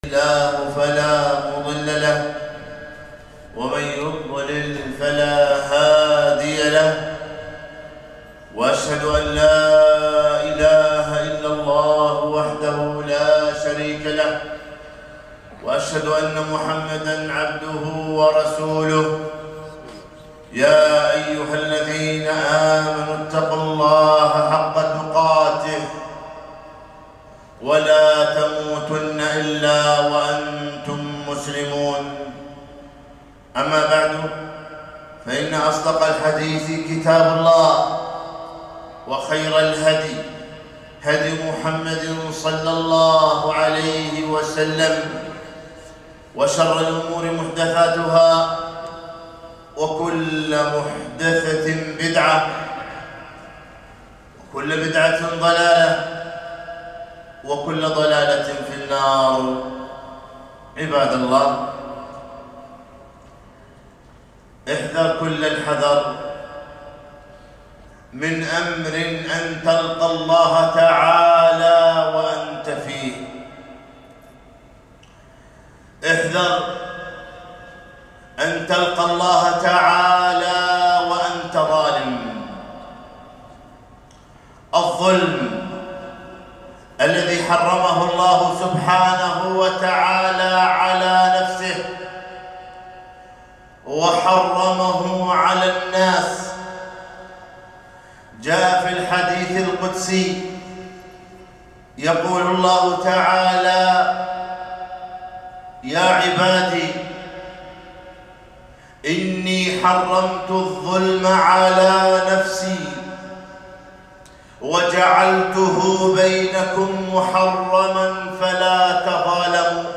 خطبة - إياك أن تلقى الله وأنت ظالم